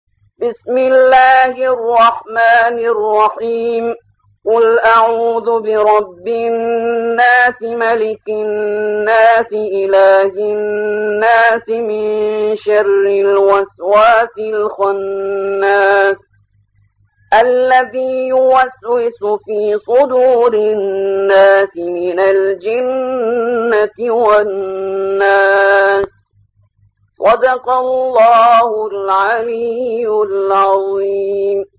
114. سورة الناس / القارئ